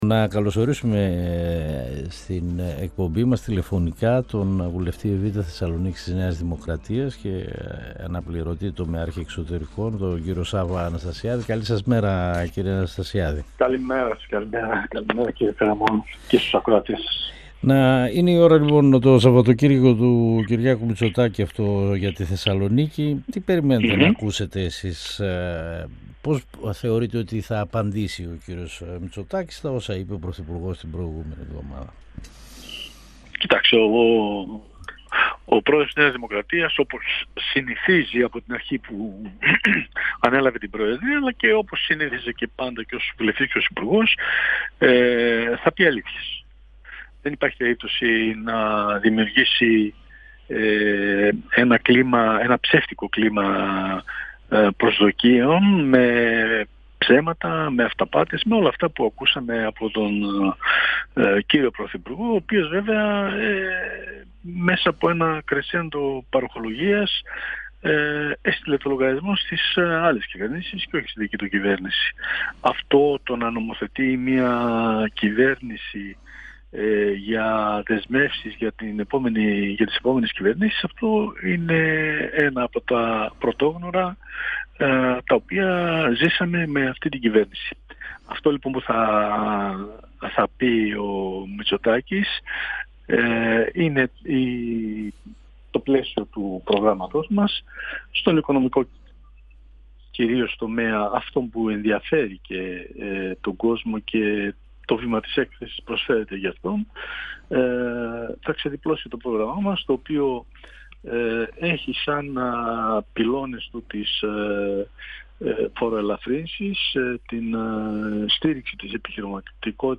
Ο βουλευτής Β’ Θεσσαλονίκης της ΝΔ Σάββας Αναστασιάδης στον 102 fm της ΕΡΤ3 102FM Συνεντεύξεις ΕΡΤ3